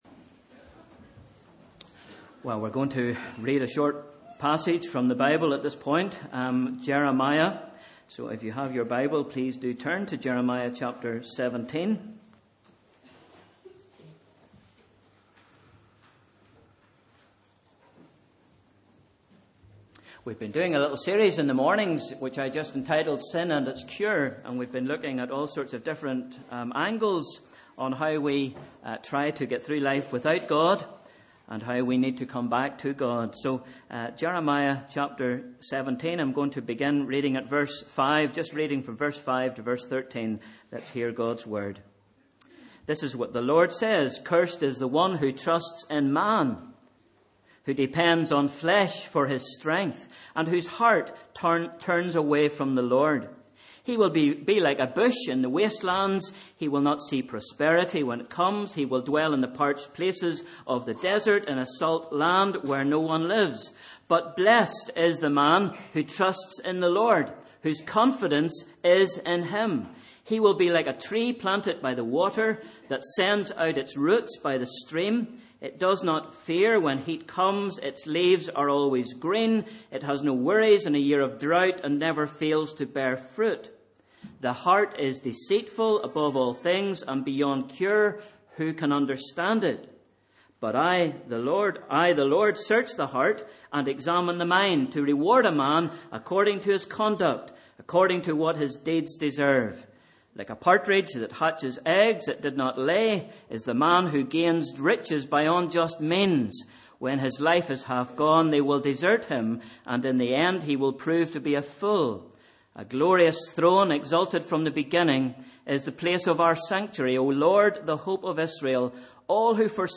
Sunday 6th March – Morning Service